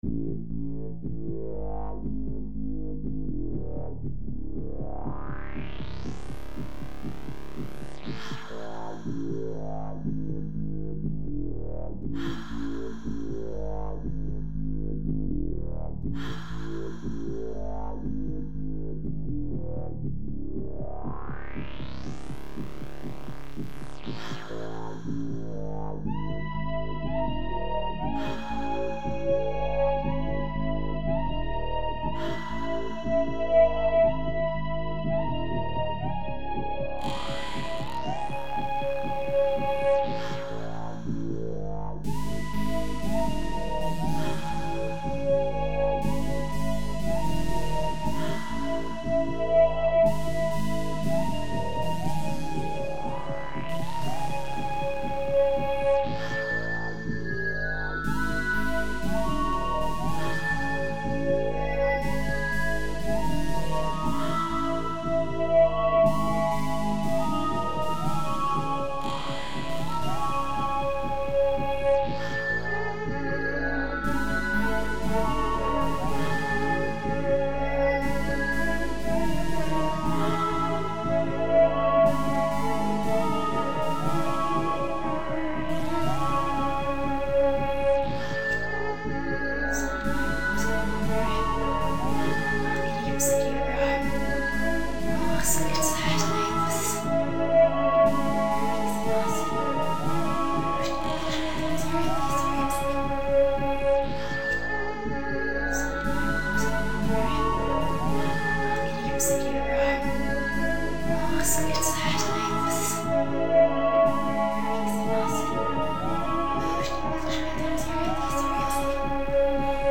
Posted in Dubstep